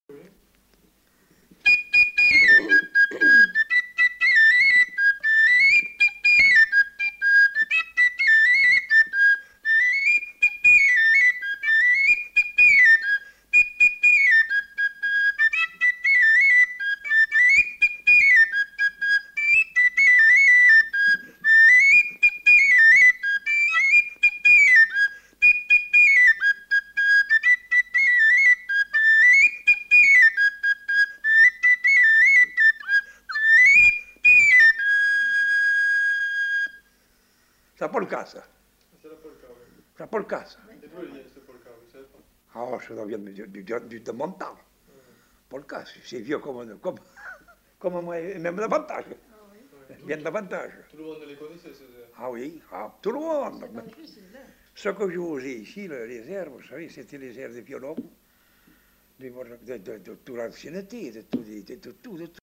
Aire culturelle : Bazadais
Département : Gironde
Genre : morceau instrumental
Instrument de musique : flûte à trois trous
Danse : polka